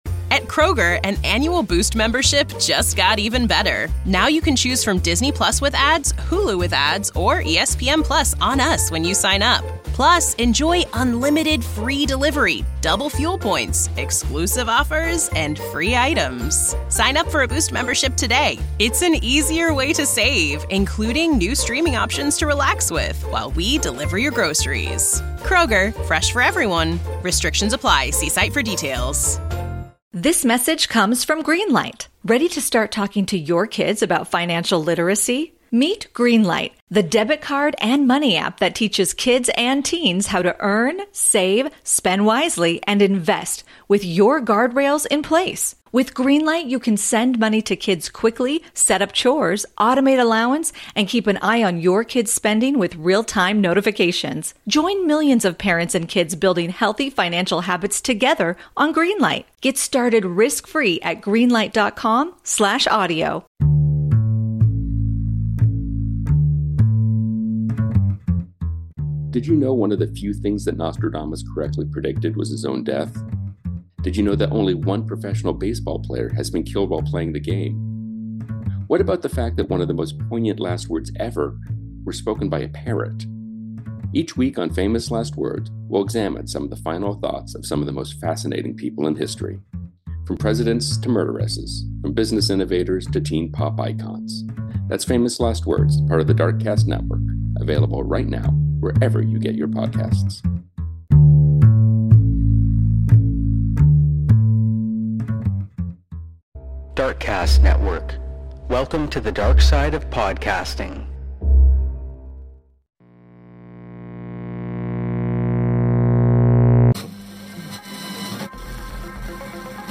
1 .453 Old Time Radio Show | Crime Doctor [Vol 2] Classic Mystery Audio Drama 49:50